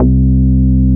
P MOOG C3P.wav